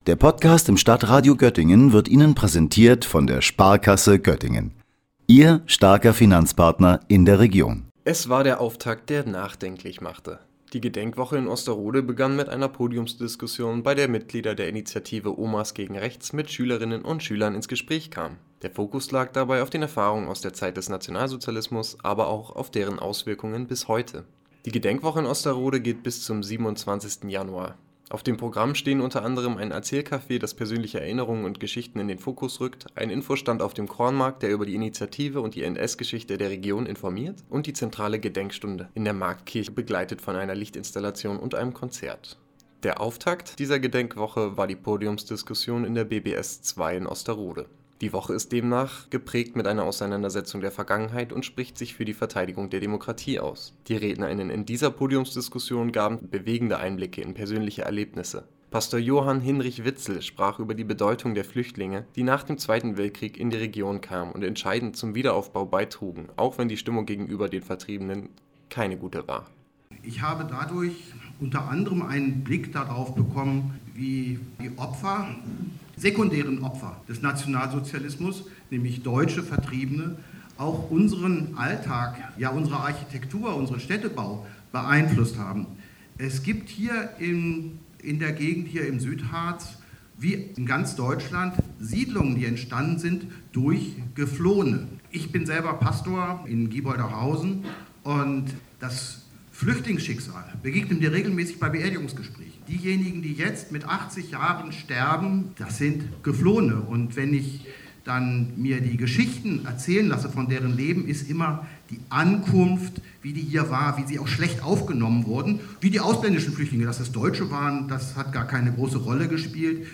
Unter dem Titel „Menschenwürde in Gefahr!“ fand dabei eine Podiumsdiskussion in der BBS II statt. Im Mittelpunkt standen die Erfahrungen und Perspektiven der Initiative „Omas Gegen Rechts“. Dabei wurde nicht nur die NS-Vergangenheit beleuchtet, sondern auch aktuelle Gefahren für die Demokratie diskutiert.